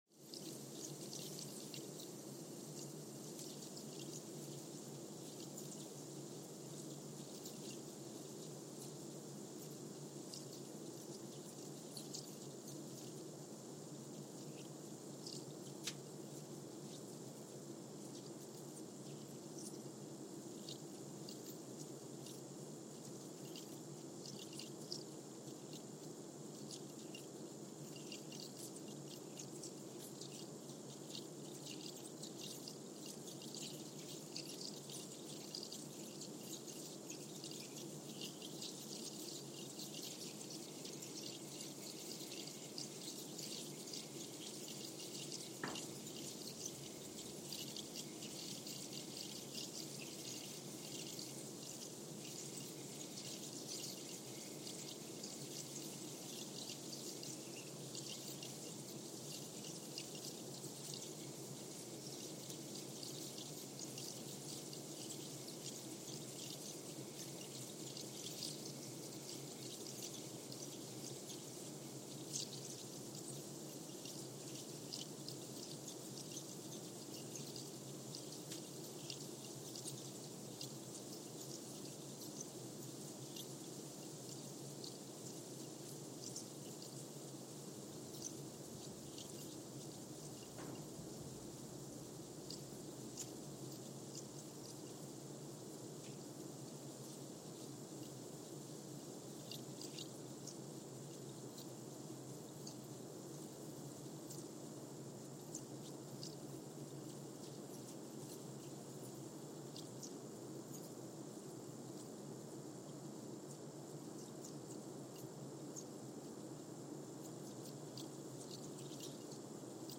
San Juan, Puerto Rico (seismic) archived on February 7, 2023
No events.
Sensor : Trillium 360
Speedup : ×1,000 (transposed up about 10 octaves)
Loop duration (audio) : 05:45 (stereo)
SoX post-processing : highpass -2 90 highpass -2 90